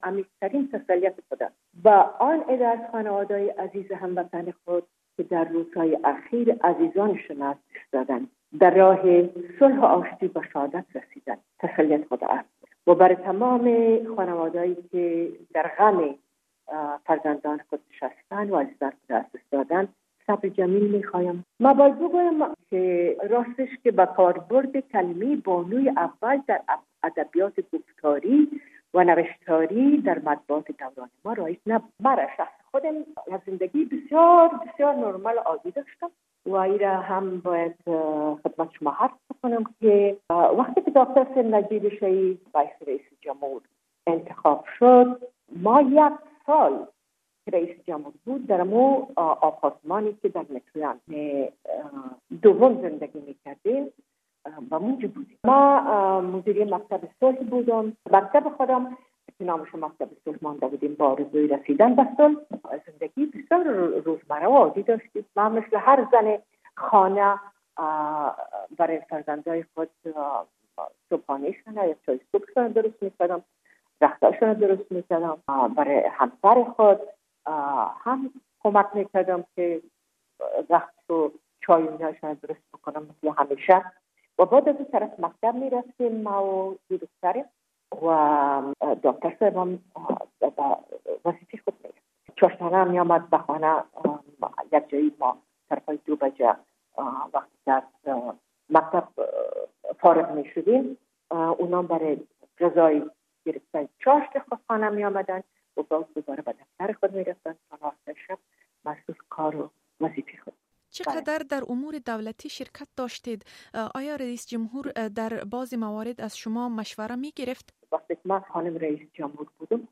مصاحبه کرده و در آغاز در مورد برنامه روزانه اش پرسیده است.